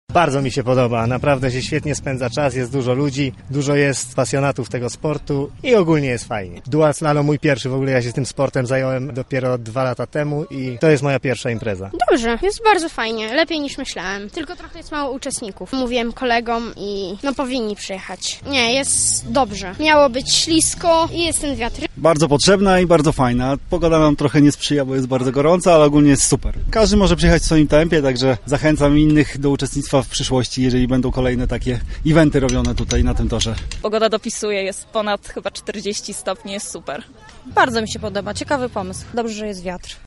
Wrażeniami z imprezy podzielili się sami uczestnicy.